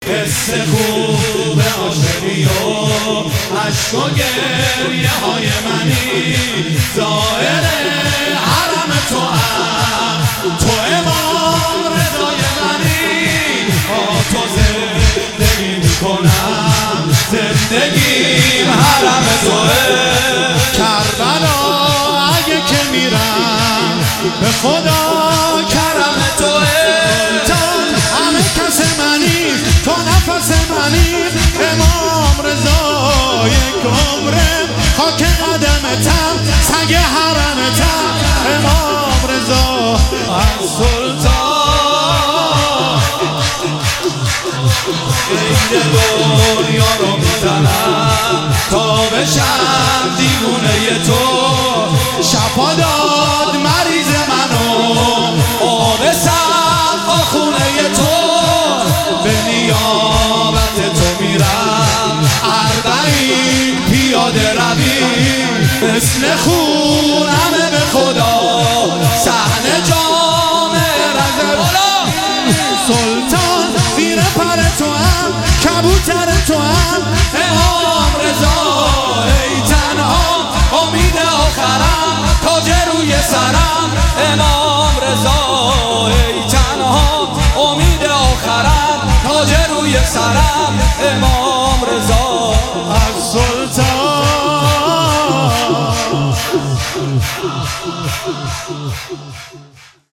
هیئت ام ابیها قم | میلاد حضرت معصومه سلام الله علیها 1401